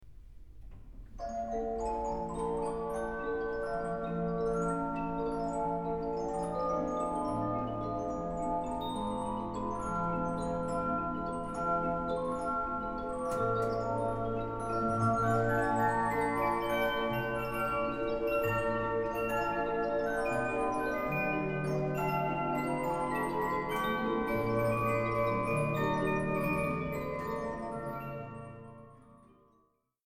Just so, most of the instruments here can be seen and not heard, but the site does have over 400 sound files, including the enchanting recording of Symphonion Eroica 38a (above), as played on a mechanical clock from 1900.